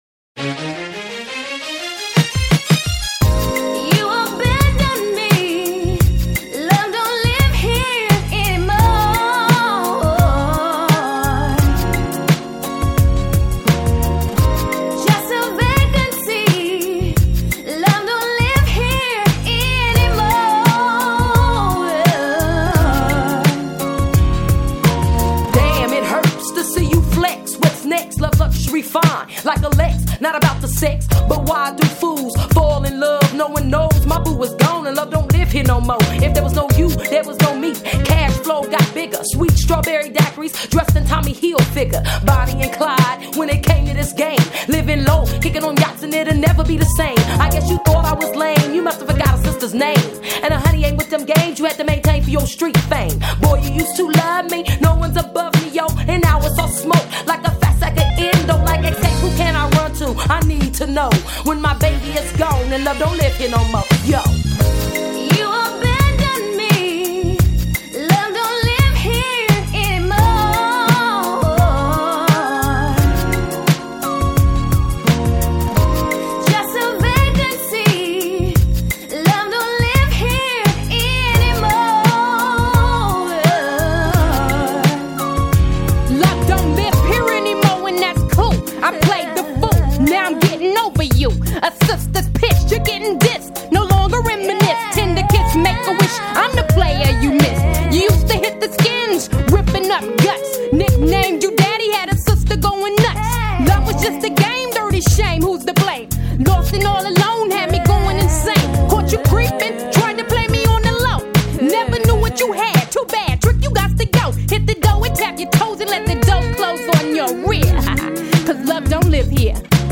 Жанр: EuroRap